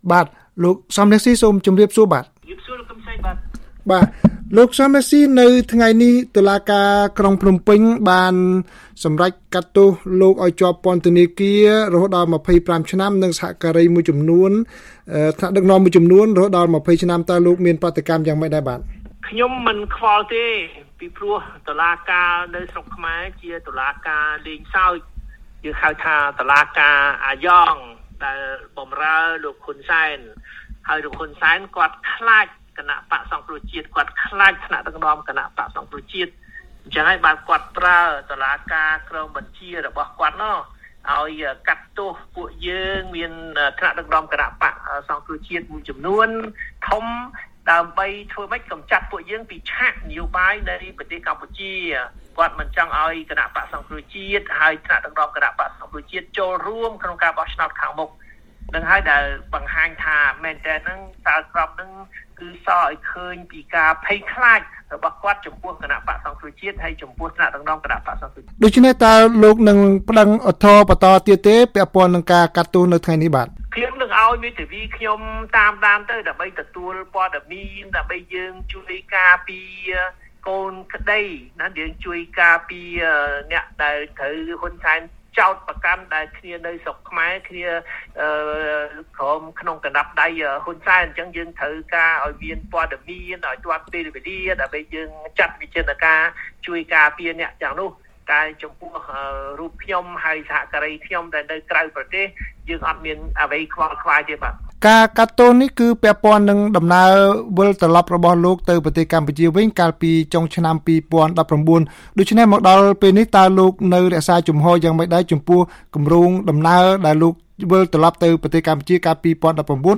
បទសម្ភាសន៍ VOA៖ លោកសម រង្ស៊ី ចាត់ទុកការកាត់ទោសពាក់ព័ន្ធនឹងដំណើរមាតុភូមិនិវត្តន៍ជាល្បិចលោកហ៊ុន សែន